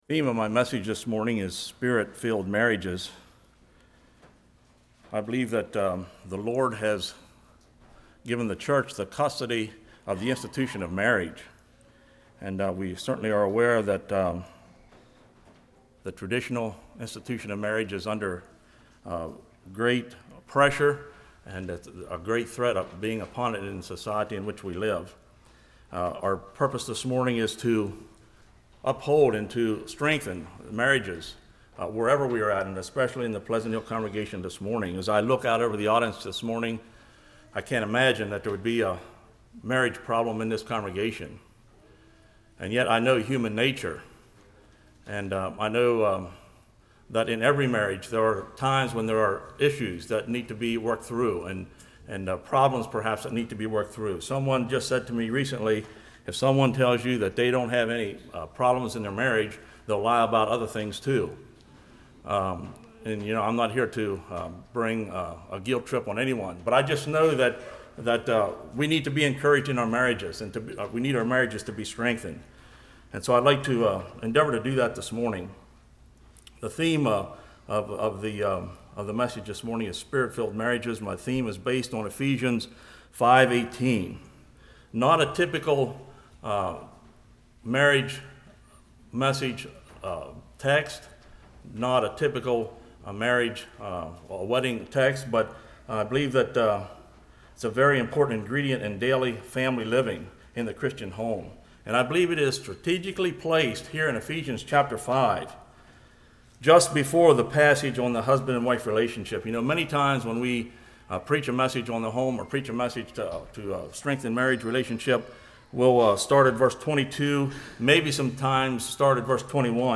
Service Type: Revival